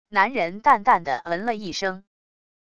男人淡淡的嗯了一声wav音频